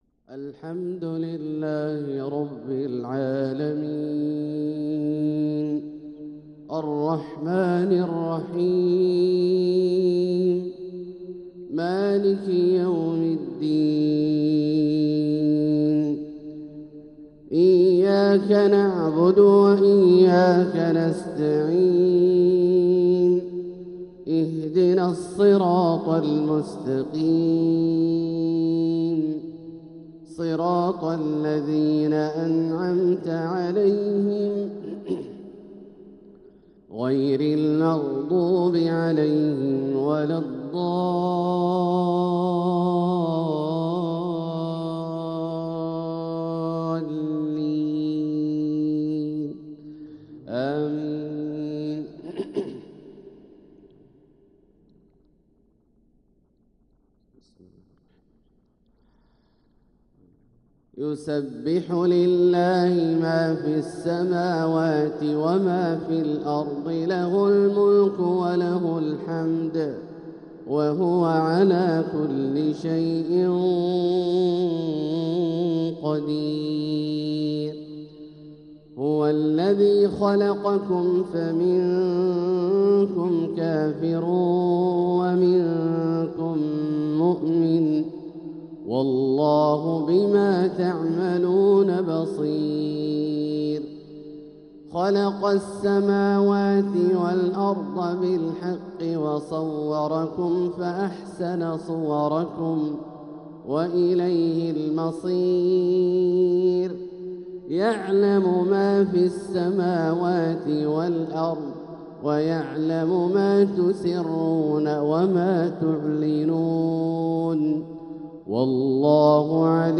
Fajr prayer from surat At-Taghabun 9-9-2024 > 1446 H > Prayers - Abdullah Al-Juhani Recitations